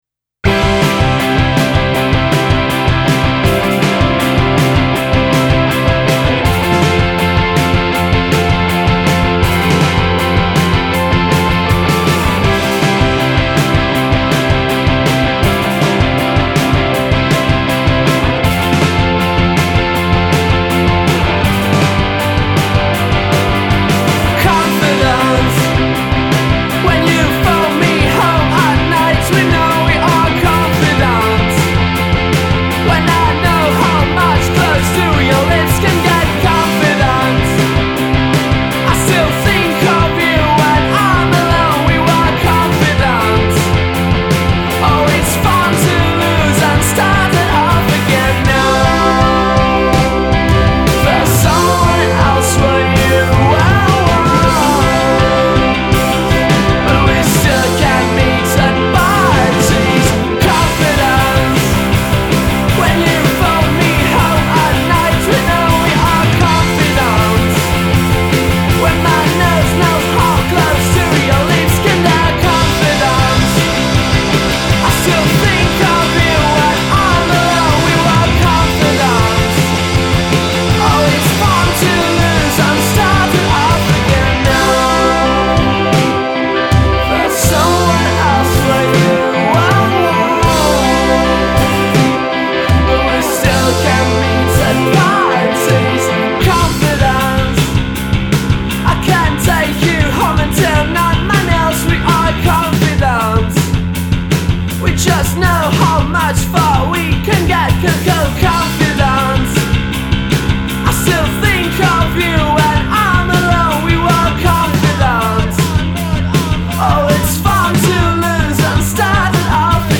incredibly catchy